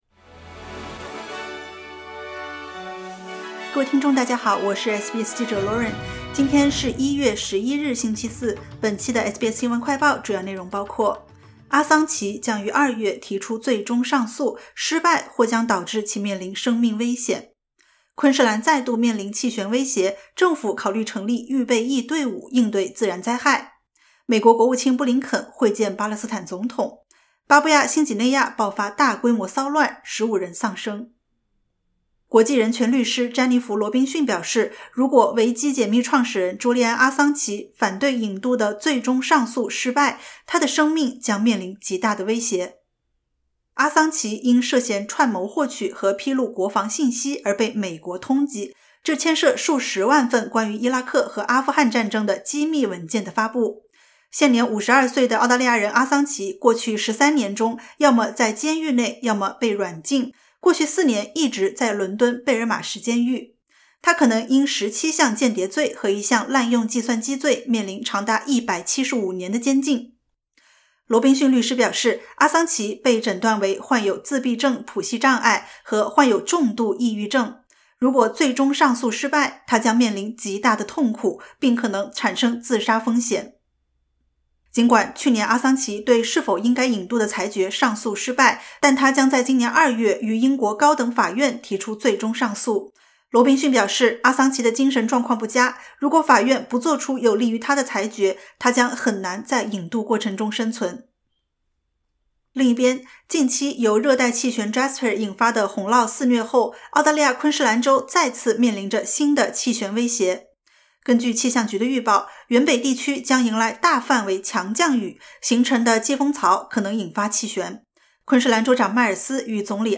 【SBS新闻快报】阿桑奇在英面临引渡风险 律师发出最后警告